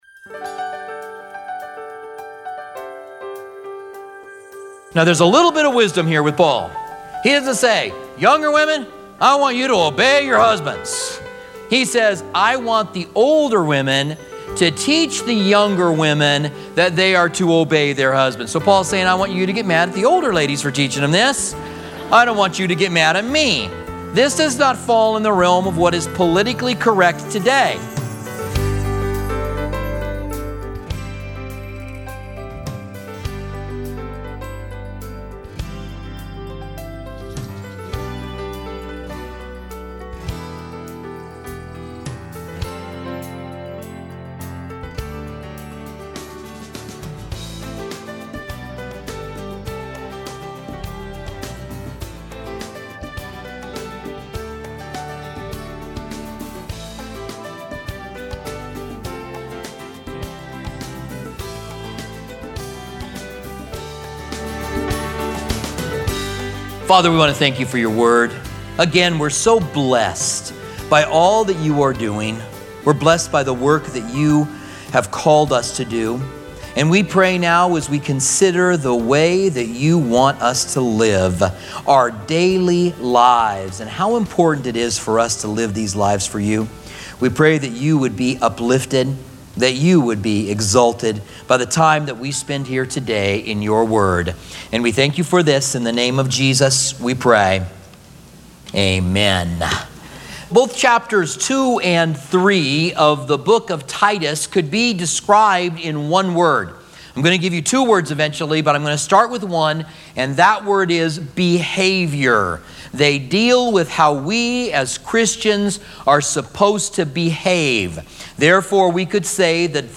Listen here to a teaching from Titus.